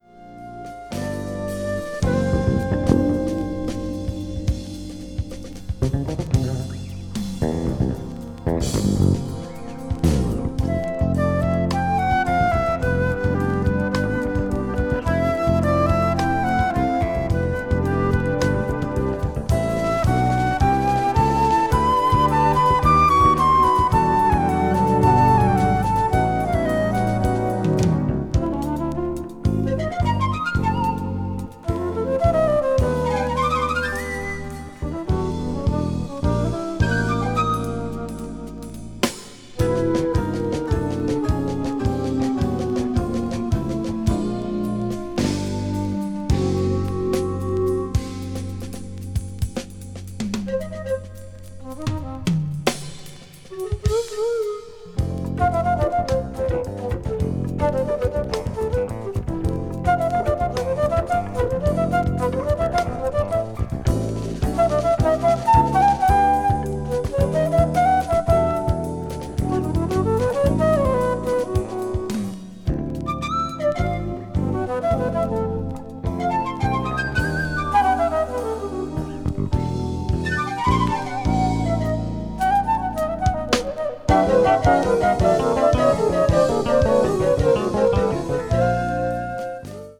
音質もすべからくソフトでマイルド。